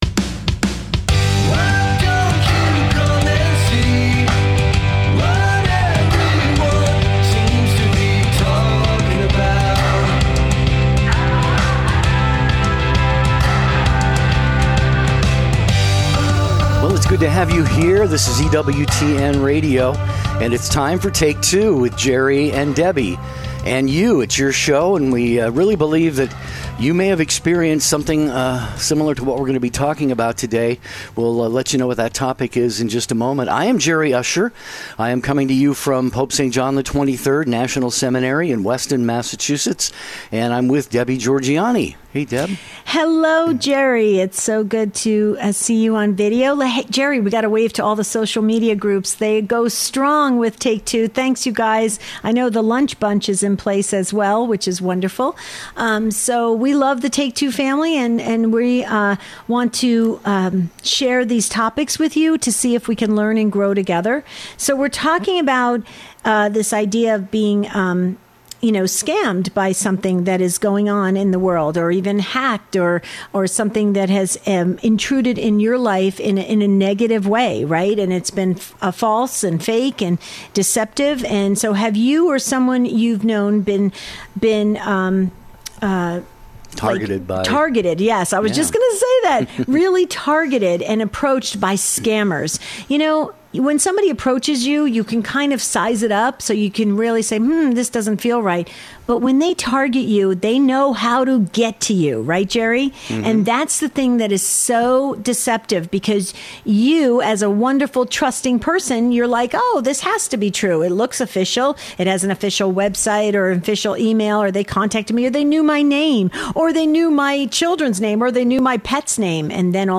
a unique live daily call-in show